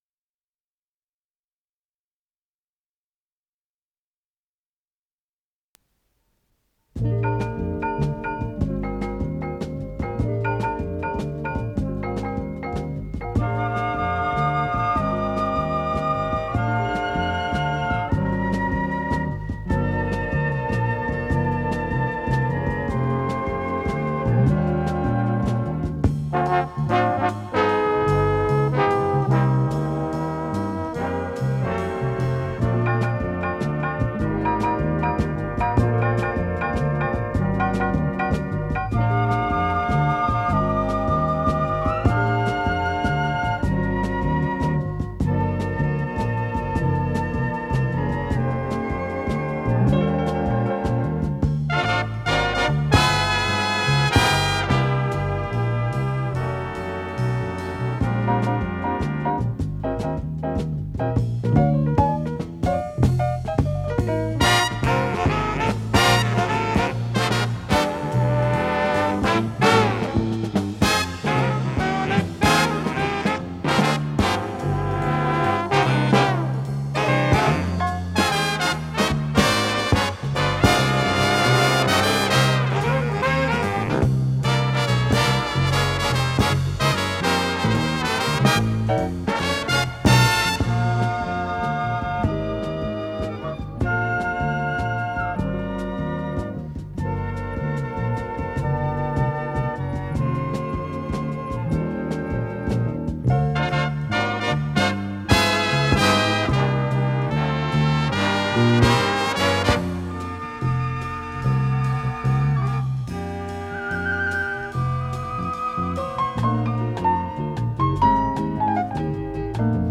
фортепиано
ВариантДубль моно